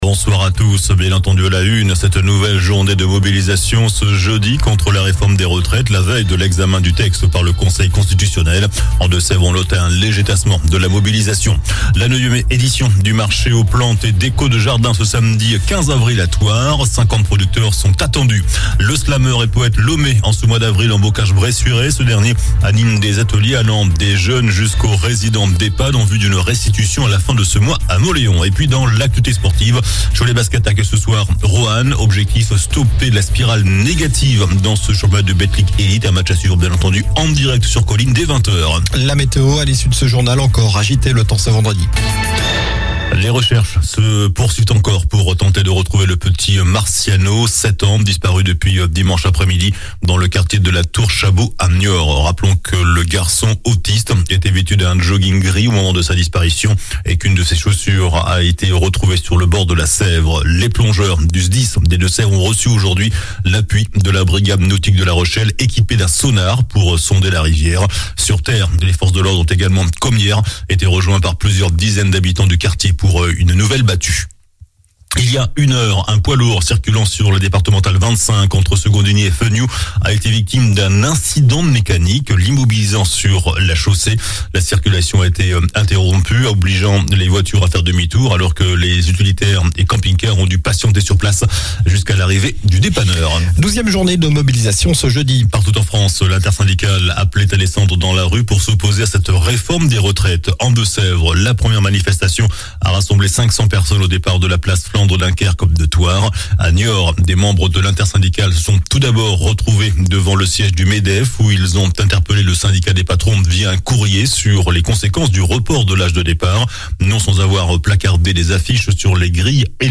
JOURNAL DU JEUDI 13 AVRIL ( SOIR )